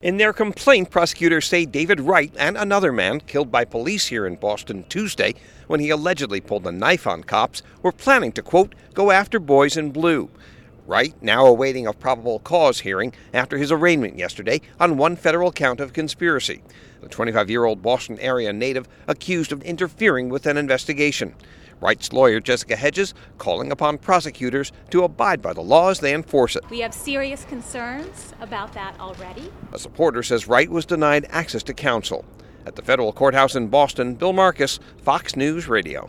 FROM THE FEDERAL COURTHOUSE IN BOSTON.